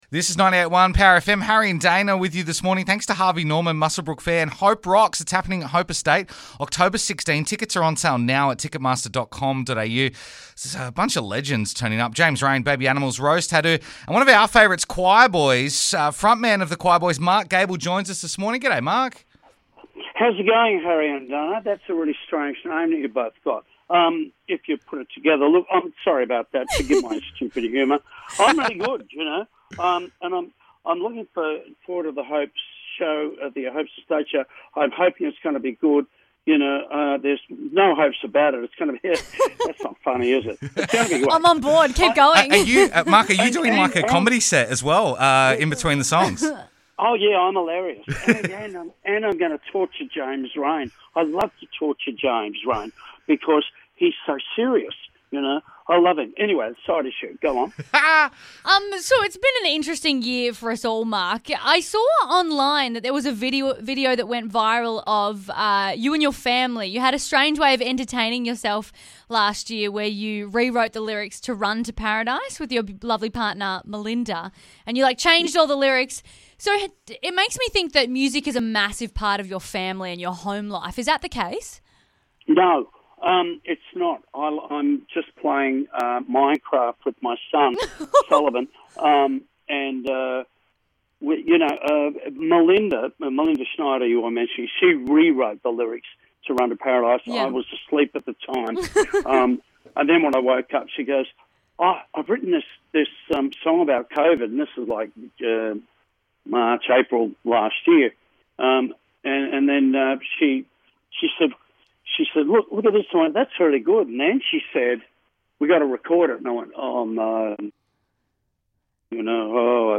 FULL CHAT: Mark Gable from The Choirboys